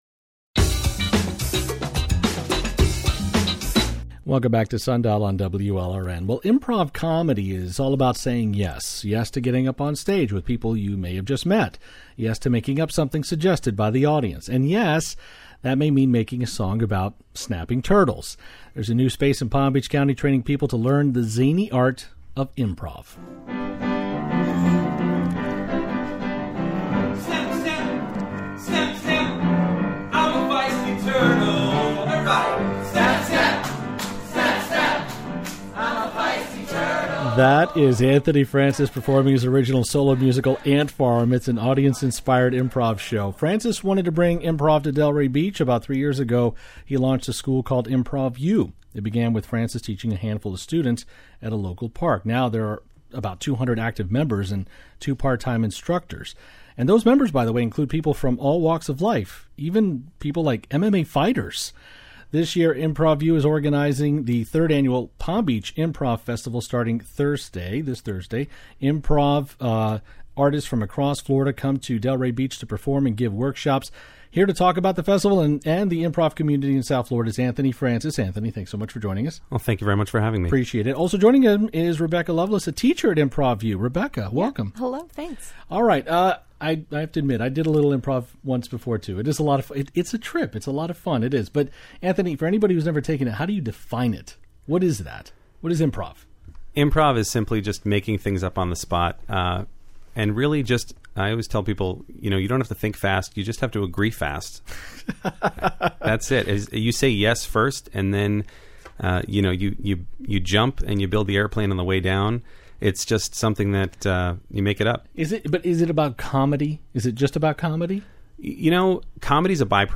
'Improv Is All About Saying Yes': Two Comedians On How Improv Has Changed Their Lives